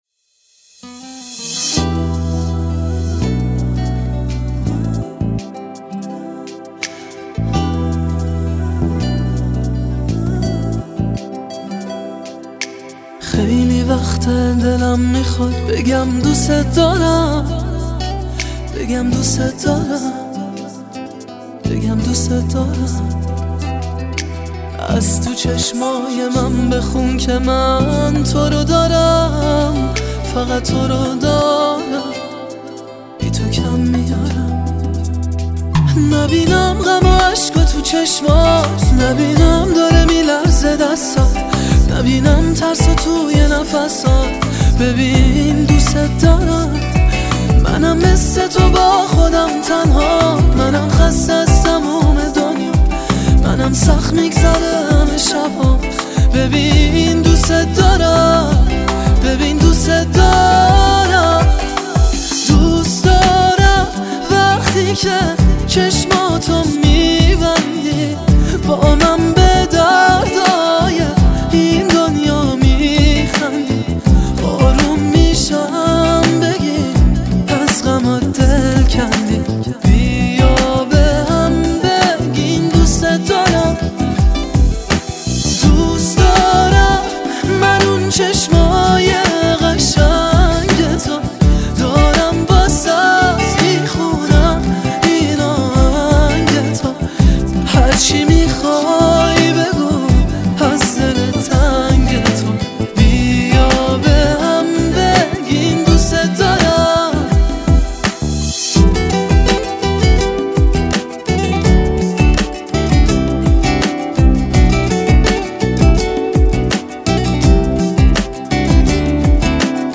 اجرا در کنسرت